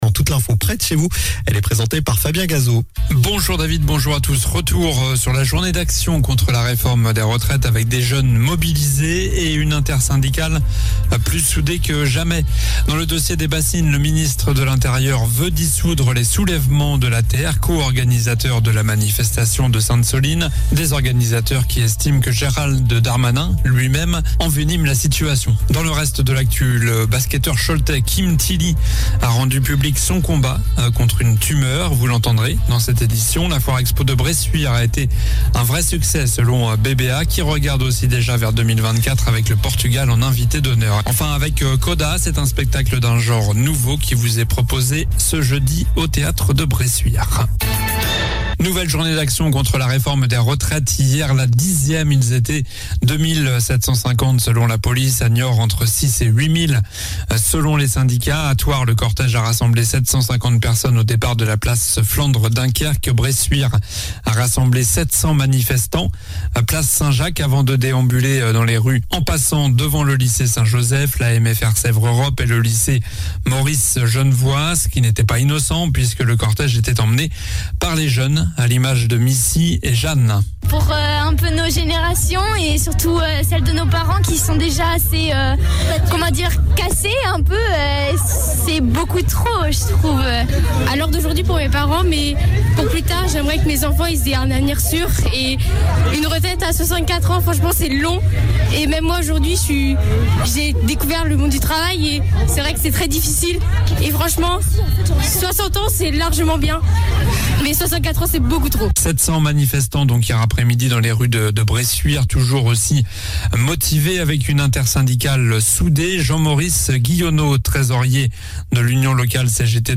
Journal du mercredi 29 mars (midi)